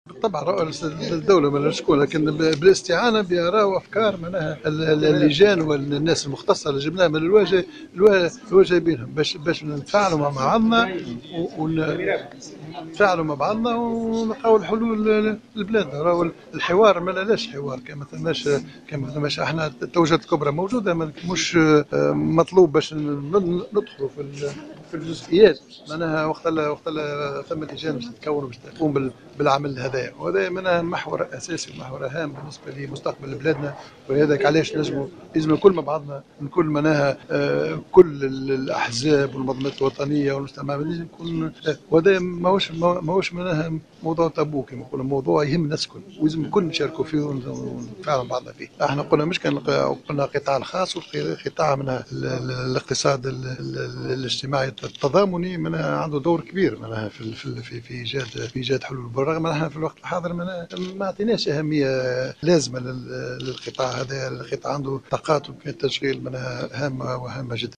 وأقر أنه لم يتم إيلاء أهمية كبرى لهذا القطاع إلى حد الآن رغم آفاق التشغيل المهمة التي يتمتع بها، بحسب تعبيره. وجاءت تصريحات رئيس الحكومة الحبيب على هامش افتتاحه صباح اليوم ورشات عمل الحوار الوطني للتشغيل.